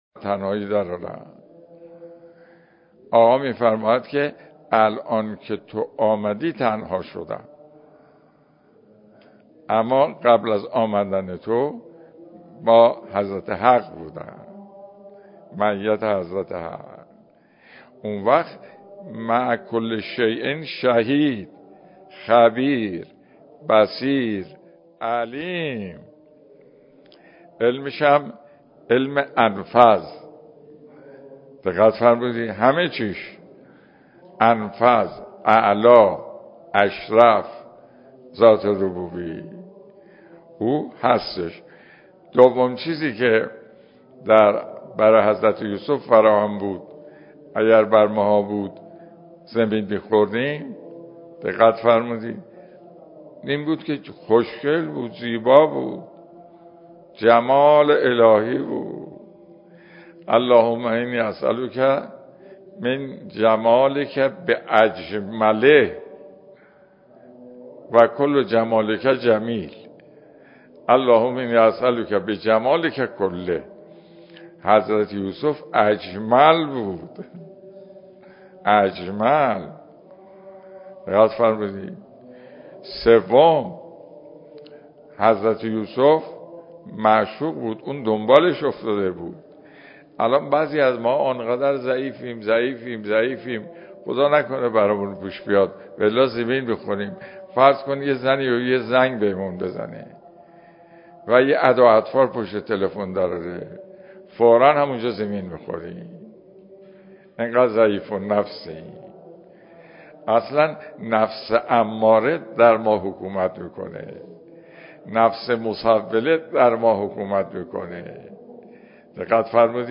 سلسله جلسه درس اخلاق